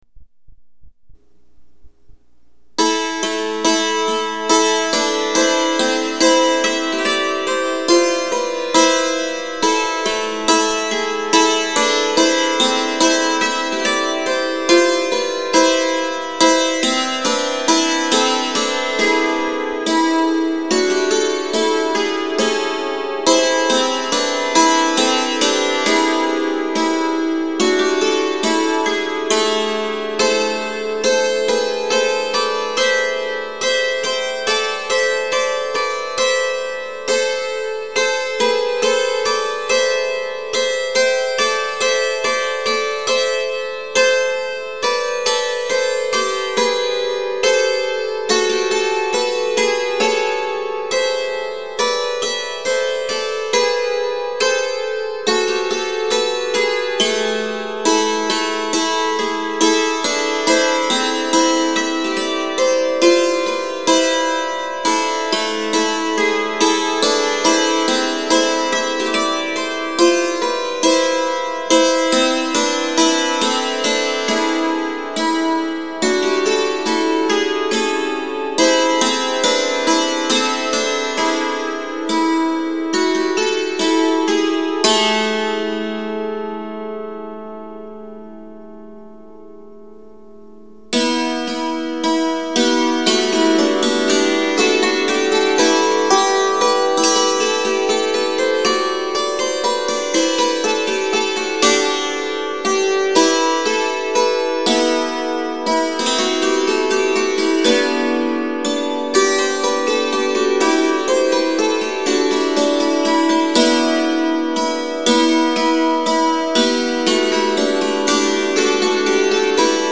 Category: Musical Performance
Brief description: A performance of three dance tunes selected from Thoinot Arbeau’s 1589 manual of French court dance, Orchesographie, played on the hammered dulcimer, an instrument well-known throughout Western Europe by the mid-15th century. The tunes selected are “Belle Qui Tiens Ma Vie,” “Jouyssance” and “Bransle d’Ecosse.”